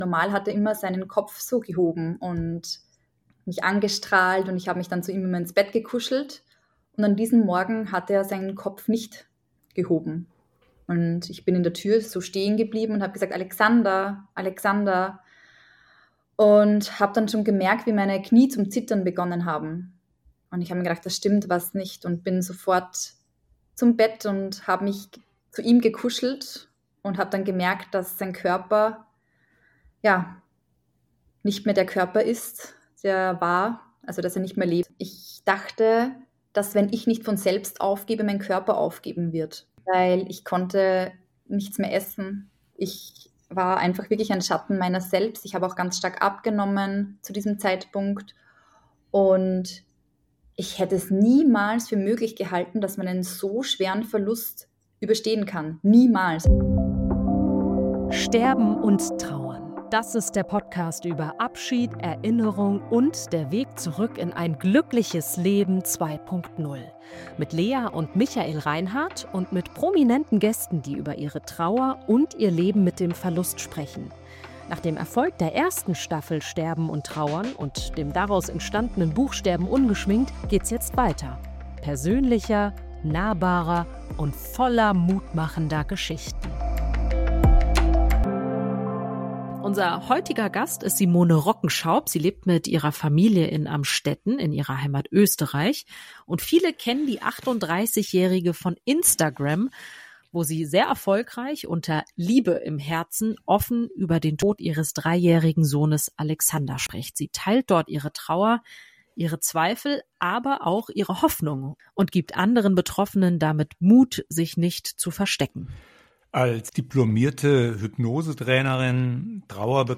Diese Folge ist ein ehrliches, aufwühlendes und zugleich hoffnungsvolles Gespräch.